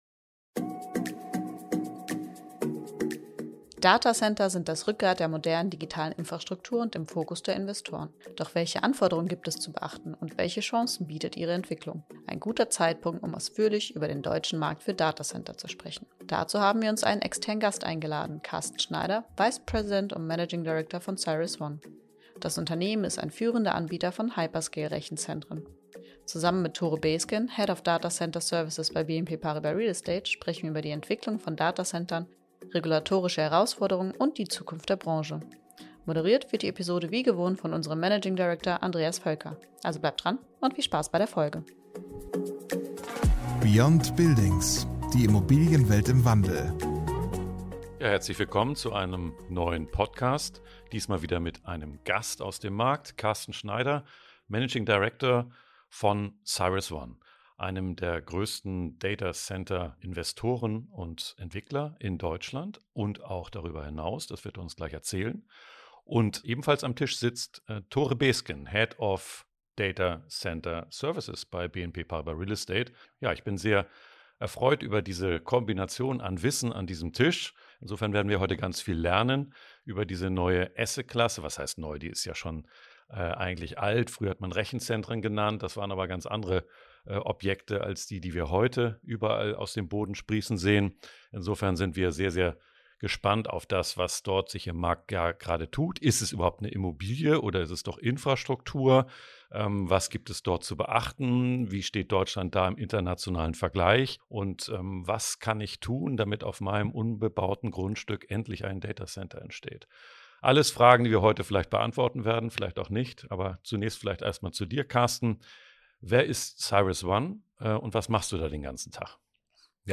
Ein guter Zeitpunkt, um ausführlich über den deutschen Markt für Data Center zu sprechen. Dazu haben wir uns einen externen Gast eingeladen: